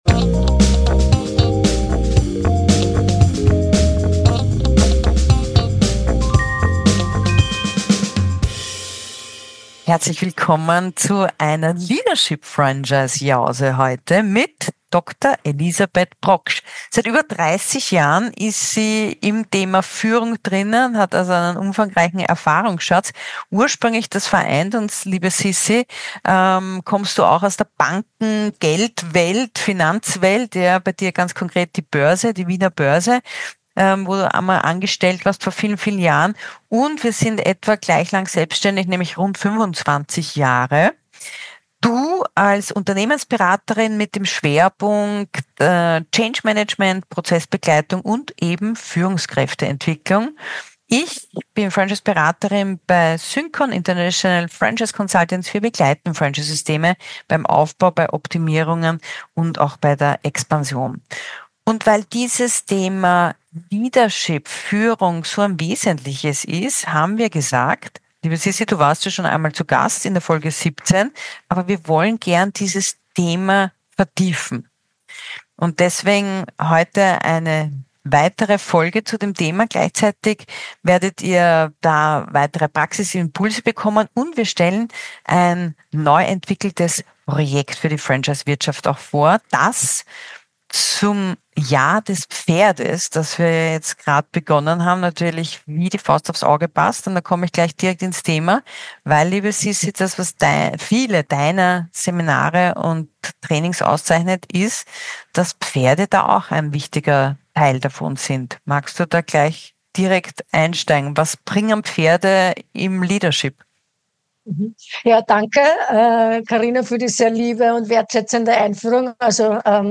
Ein Gespräch über Führungsverständnis, Nachdrücklichkeit, Werteorientierung, innere Programme und Lernfelder